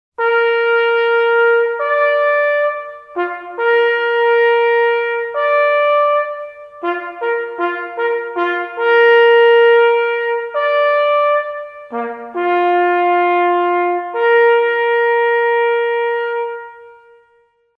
Na rozpoczęcie pokotu sygnaliści grają sygnał